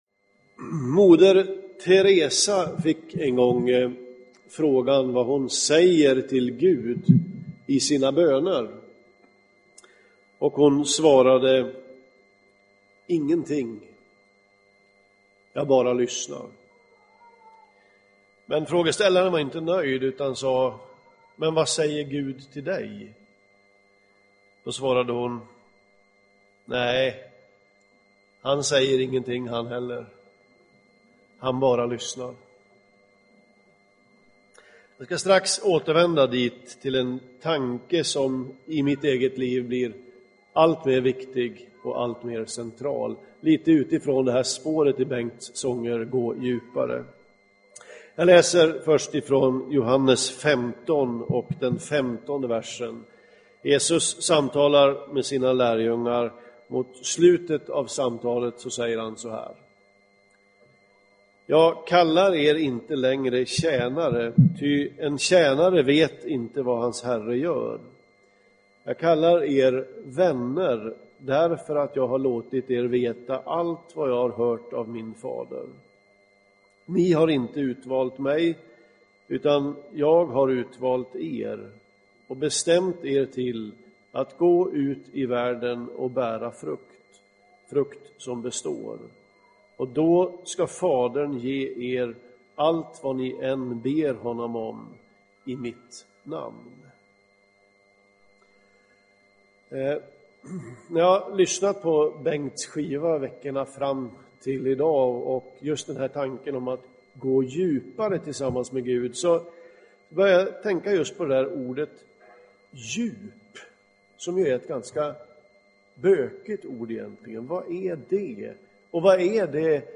Inspelad i Smyrnakyrkan, Göteborg 2013-03-17.